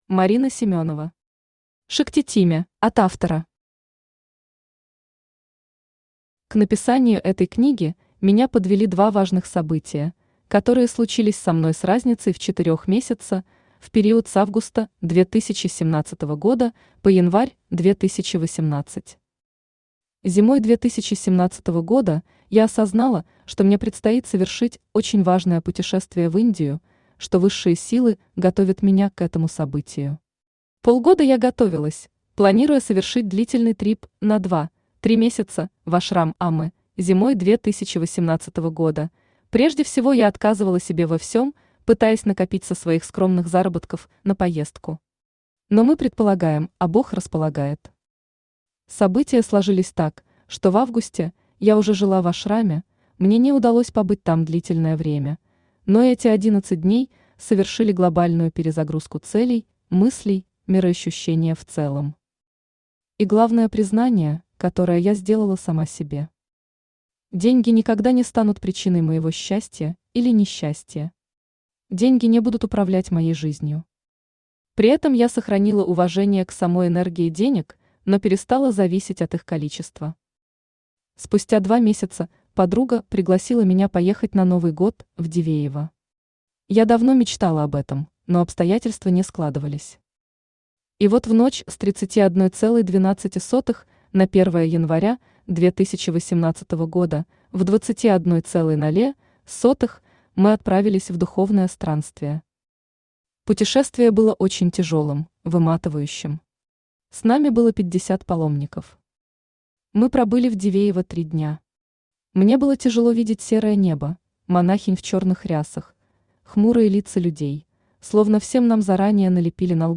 Aудиокнига Шактиtime Автор Марина Семенова Читает аудиокнигу Авточтец ЛитРес.